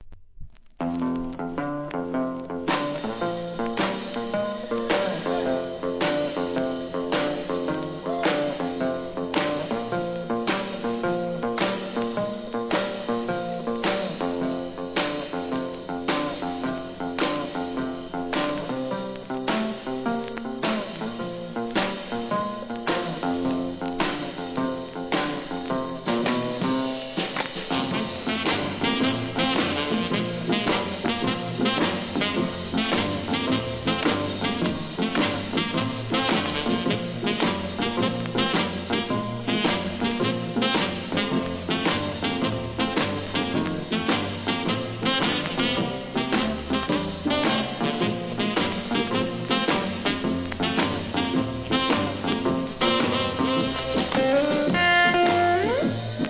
SOUL/FUNK